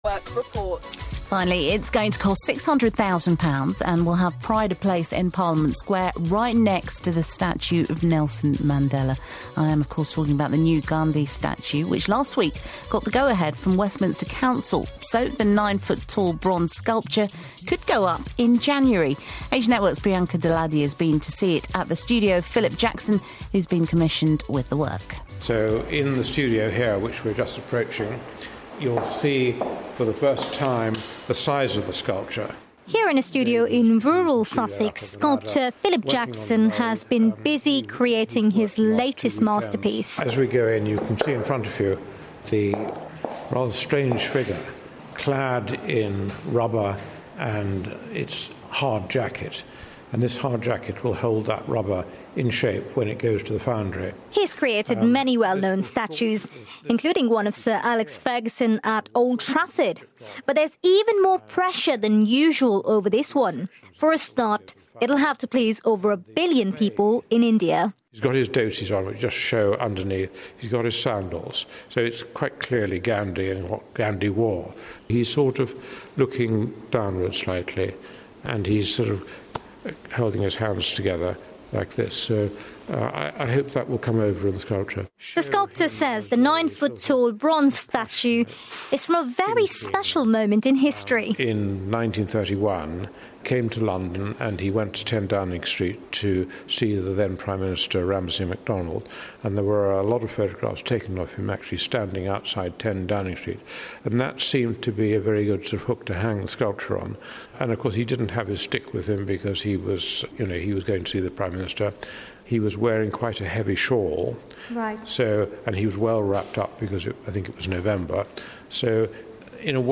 He's been speaking exclusively to Asian Network.